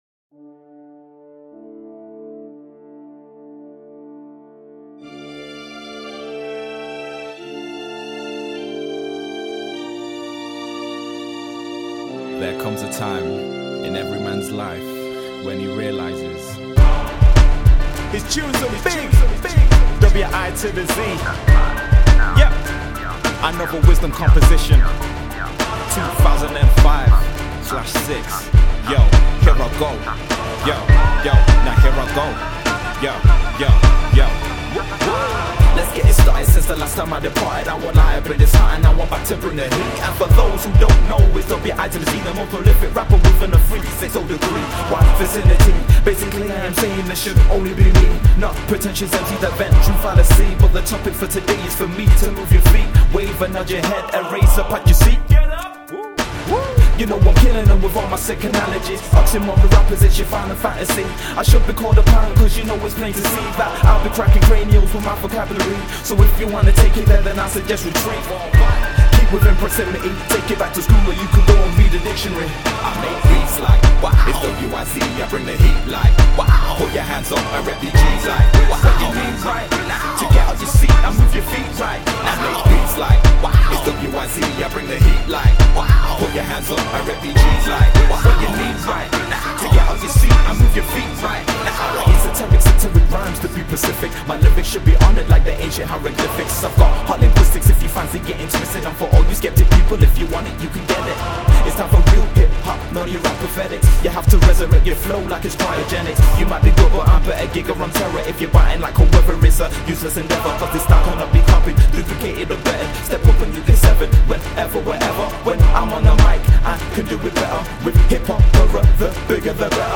a freash versatile approach to modern british hip-hop